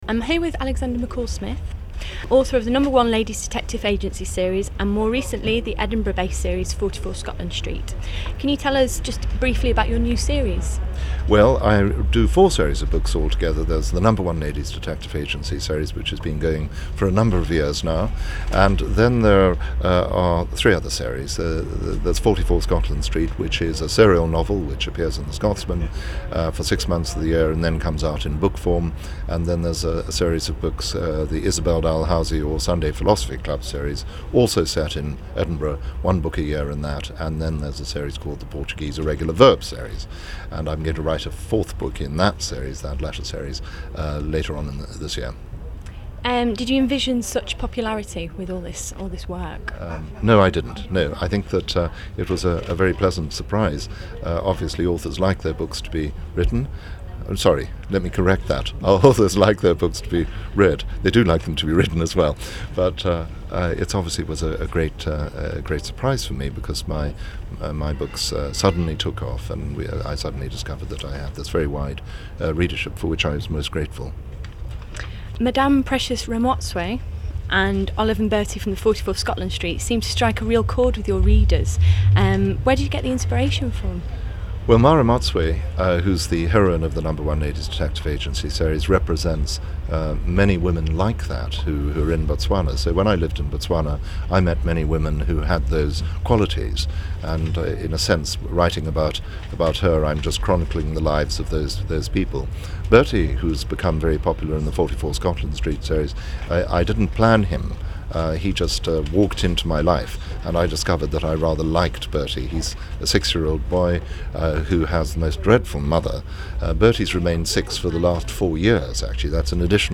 Alexander McCall Smith at the Edinburgh International Book Festival 2007
Explore some of our author events with this series of podcasts featuring extracts from shows, exclusive interviews and audience feedback.
Alexander Mcall Smith interview.mp3